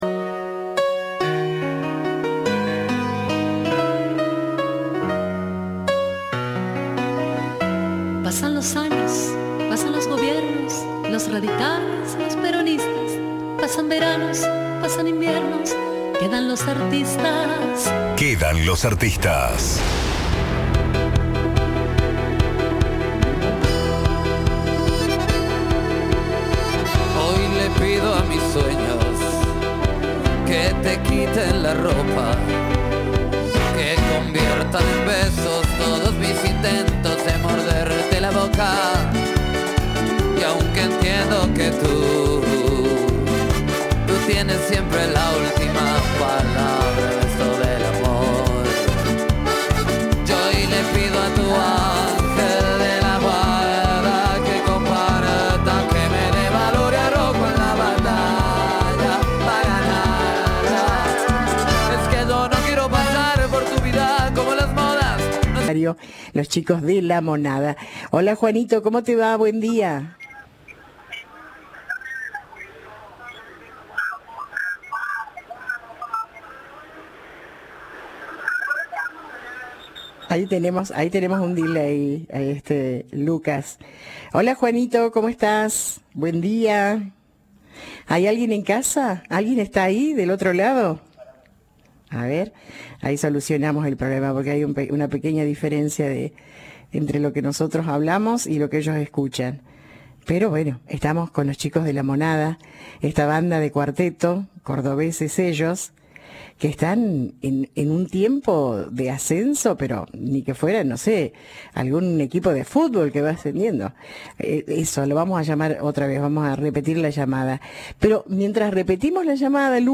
Reviví la entrevista en Noche y Día.